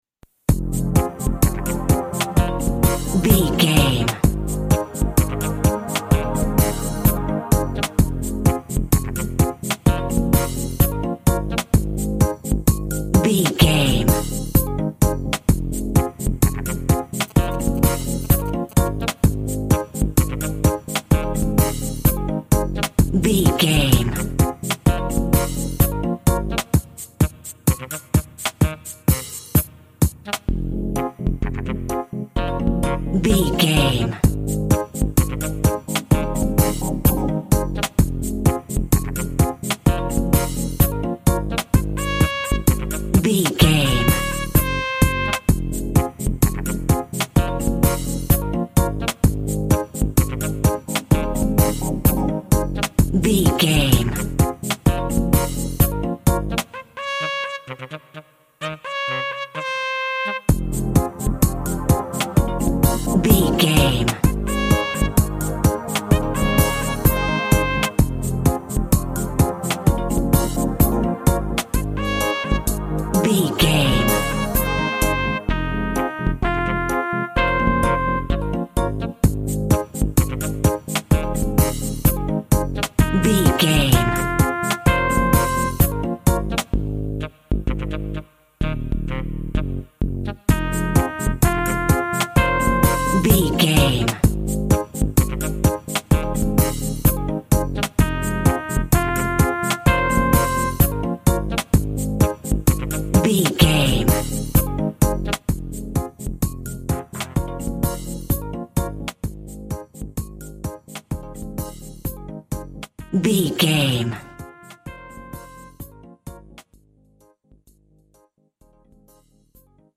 Also with small elements of Dub and Rasta music.
Ionian/Major
A♭
tropical
guitar
brass
pan pipes
steel drum